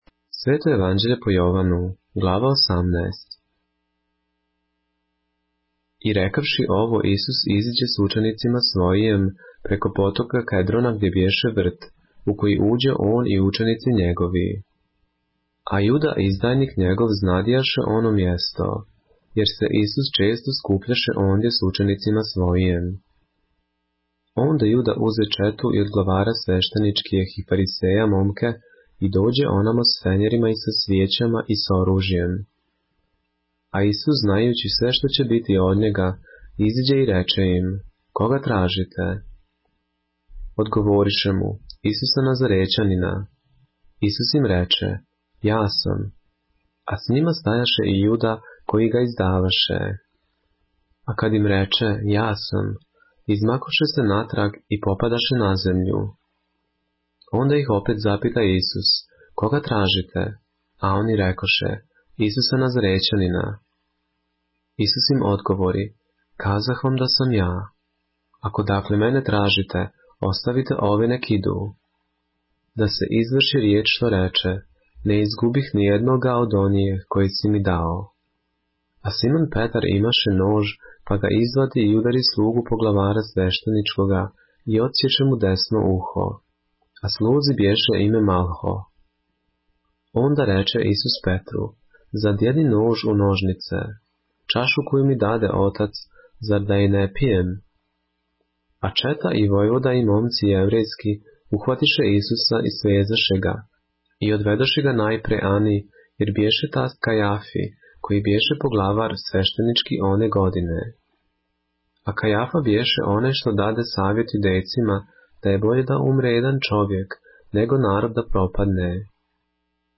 поглавље српске Библије - са аудио нарације - John, chapter 18 of the Holy Bible in the Serbian language